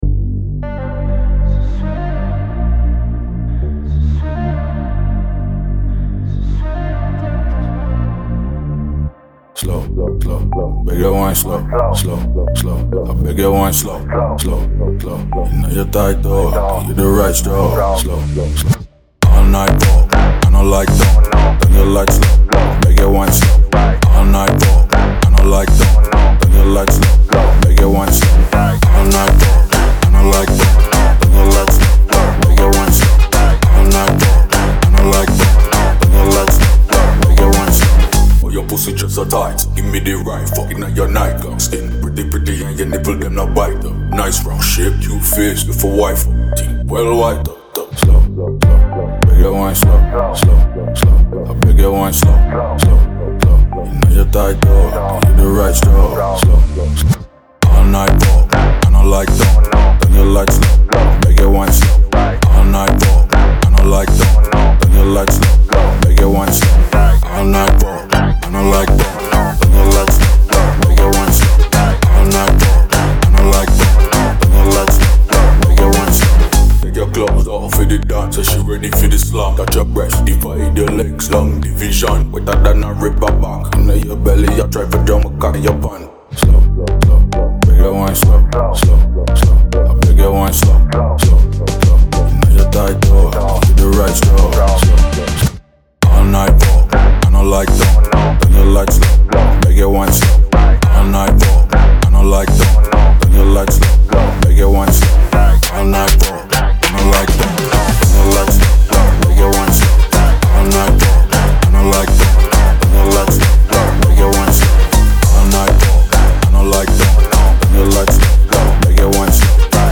это динамичный трек в жанре EDM
сочетание мелодичных вокалов с мощными электронными битами